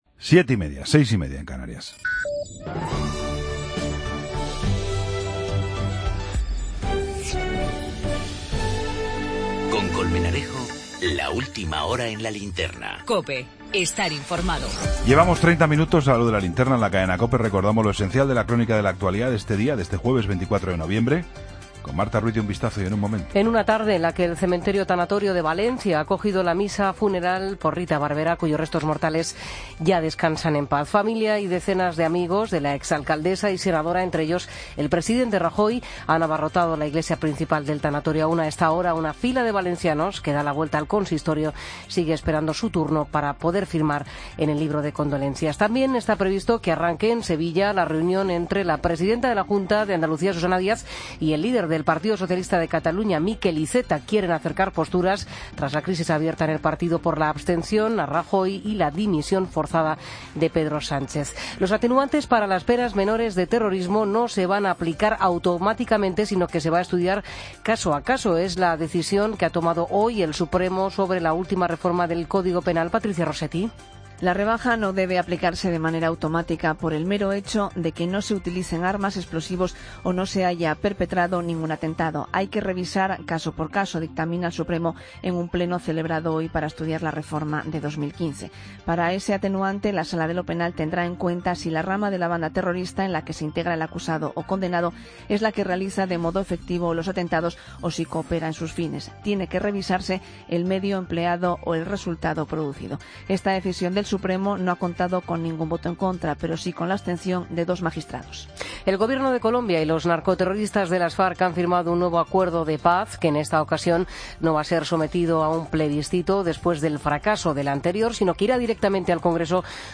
Ronda de corresponsales. Sección de Emprendedores.